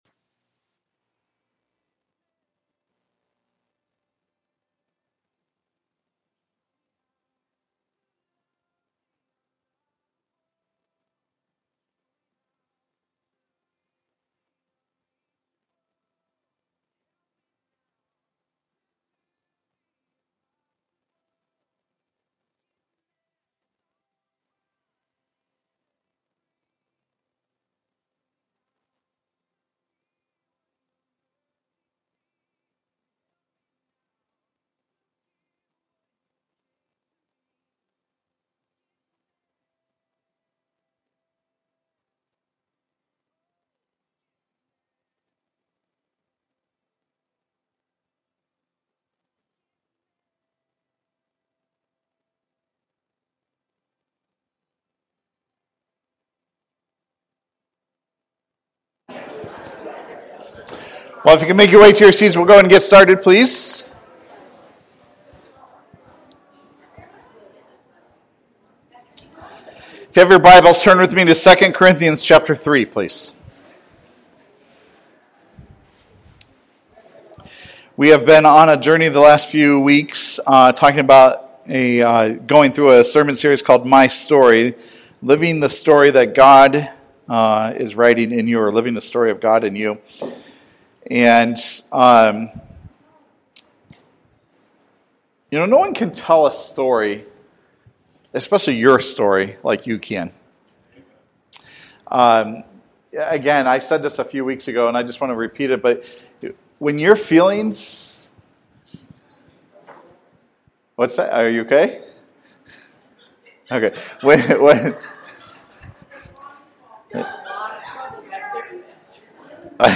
Sermons by Passion Community Church